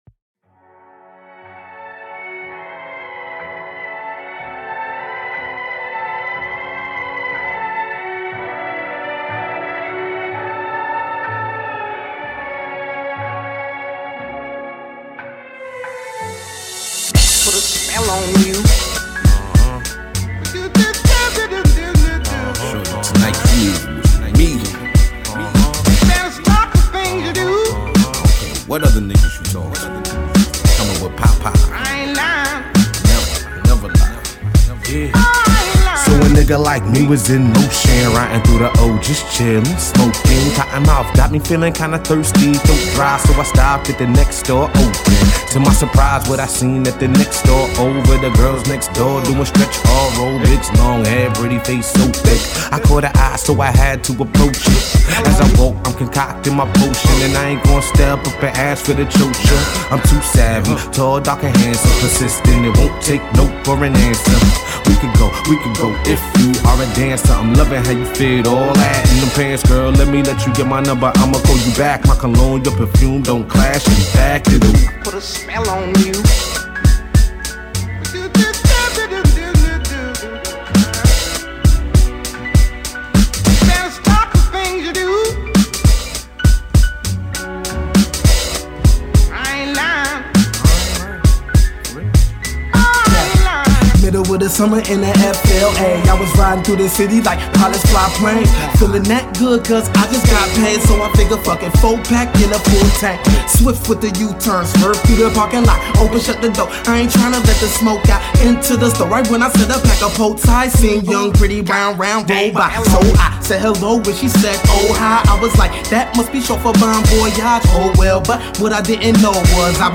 dope flows
Hip-Hop
which consists of four MC’s from the southeast